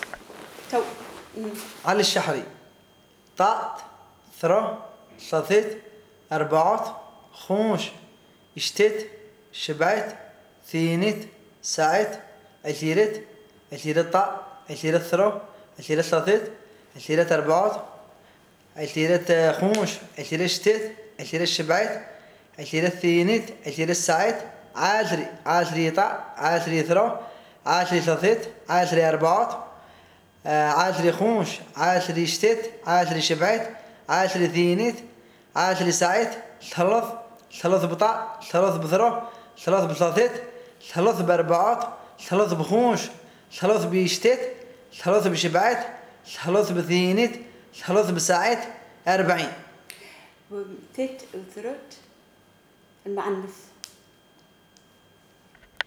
Śḥerɛ̄t 1-40, masculine numbers
śḥerɛ̄t_1-40_masculine_numbers.wav